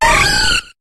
Cri de Feuforêve dans Pokémon HOME.